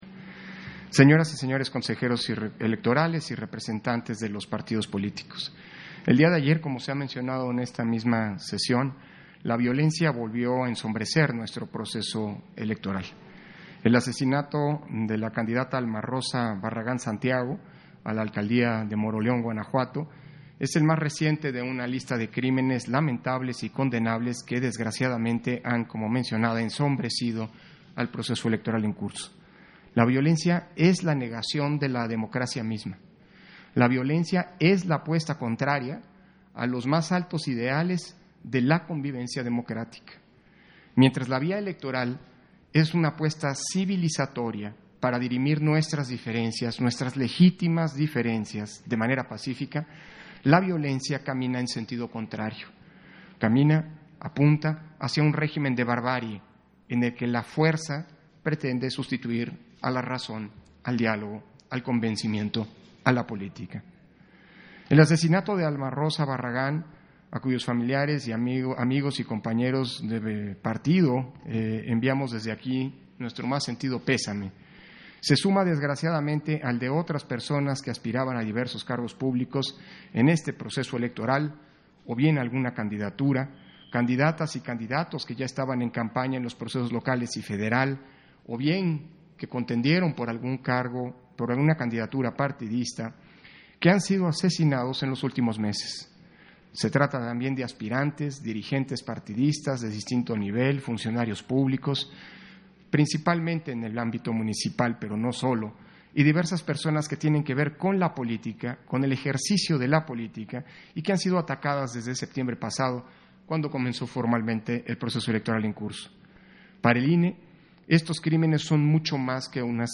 260521_AUDIO_INTERVENCIÓN-CONSEJERO-PDTE.-CÓRDOVA-PUNTO-24-SESIÓN-ORDINARIA - Central Electoral